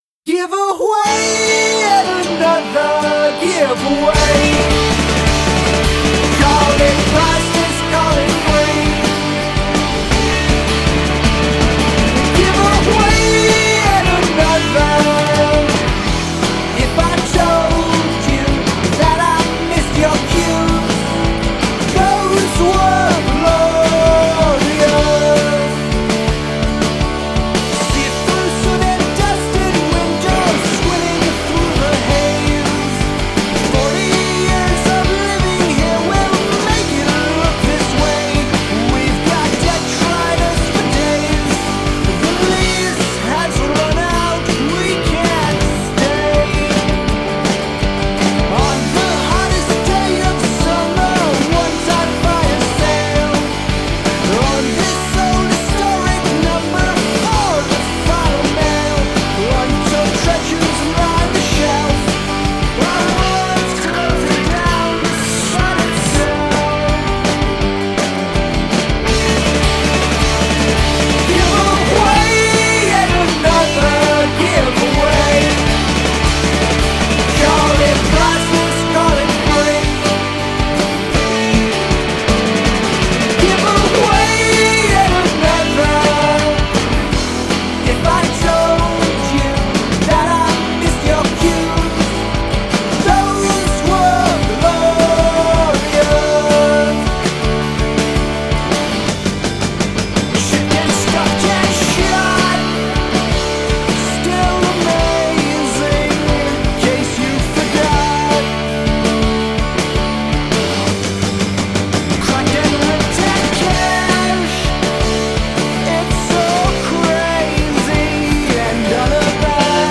perfect pop resurrection